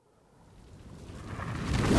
cast 2sec.wav